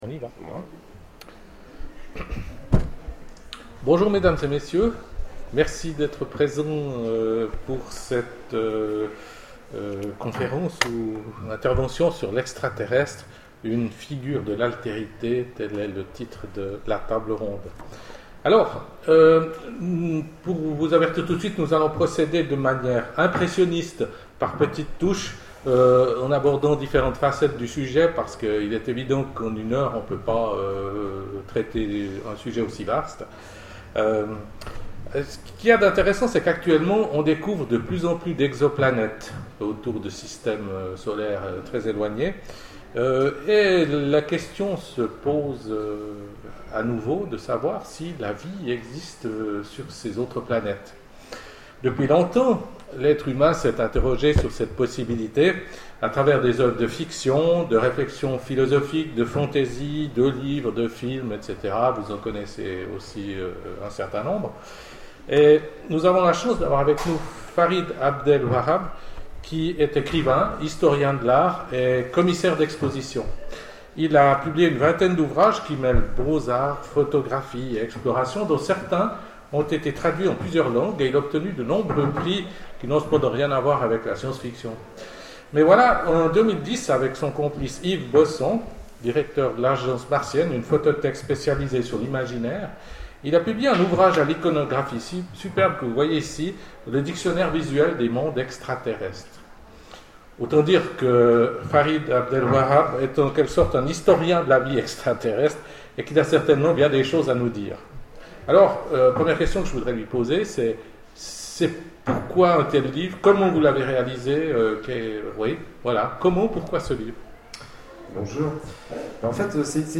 Imaginales 2012 : Conférence L'extraterrestre, une figure de l'altérité ?
- le 31/10/2017 Partager Commenter Imaginales 2012 : Conférence L'extraterrestre, une figure de l'altérité ?